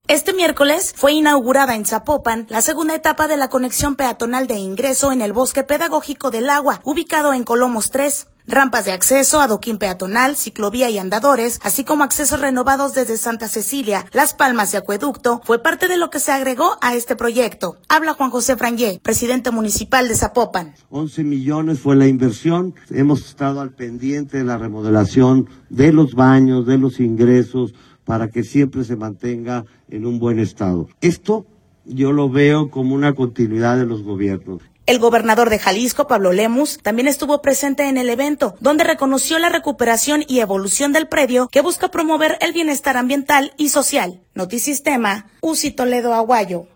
Habla Juan José Frangie, presidente municipal de Zapopan.